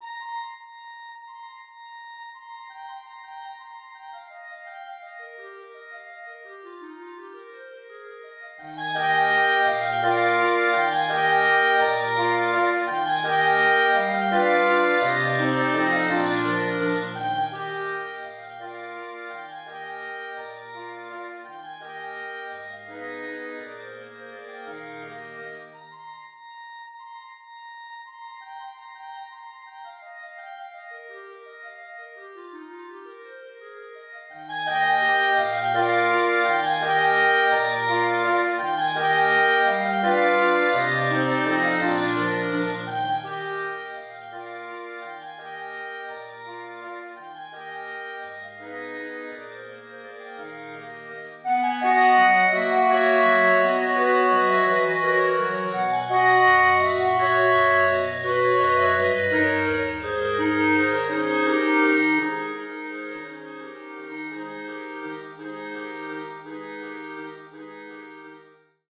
Klarinettenquintett